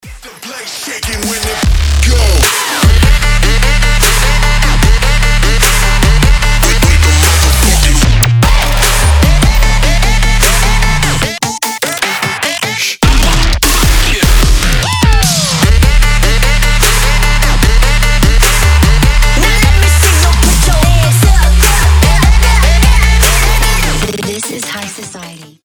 громкие
жесткие
мощные
взрывные